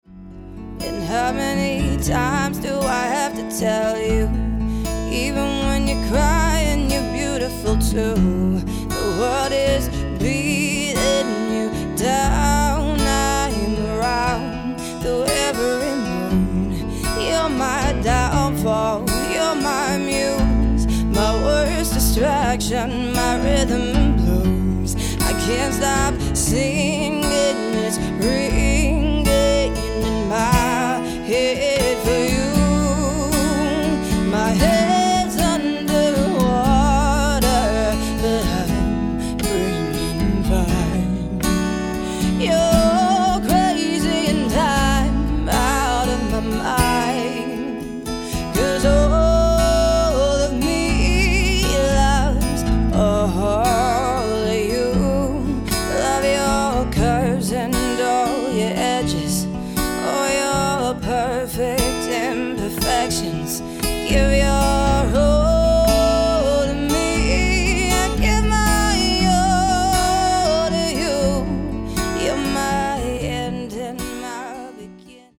Vocals | Guitar
fronted by the soulful vocals